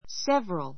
sévrəl